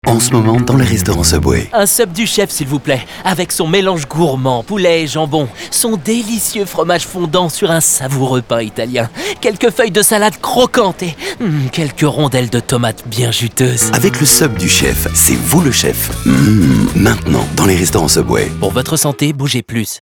Comédien Voix Off,voix grave;voix souriante ,voix tonique,voix sensuel,
Sprechprobe: Sonstiges (Muttersprache):